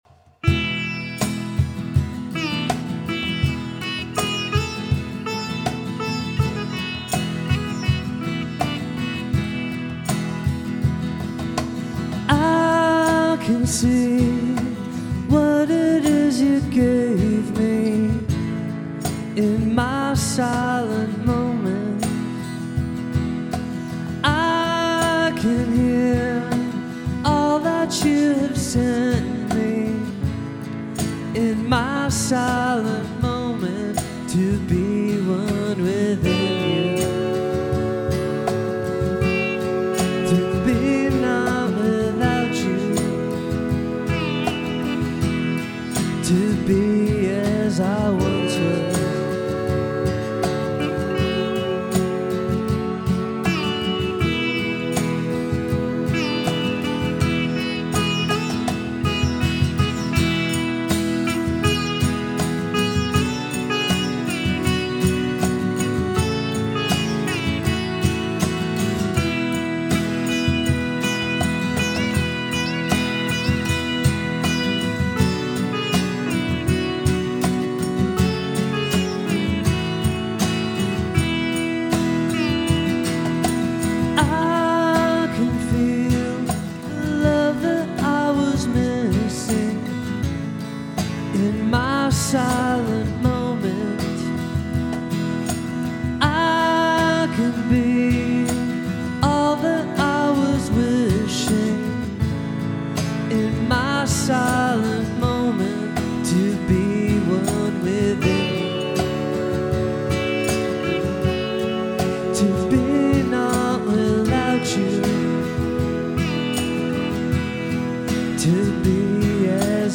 Canadian Psych/Alternative band
Psych meets Alt – is happy.
acoustic session